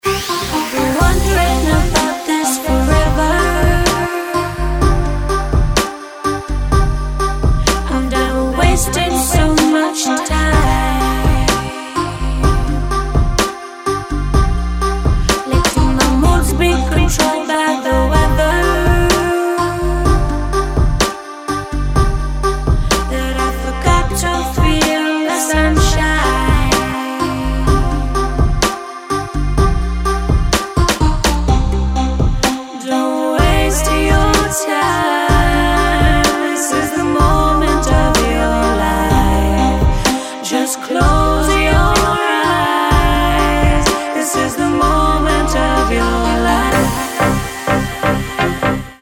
Клубные рингтоны Загрузил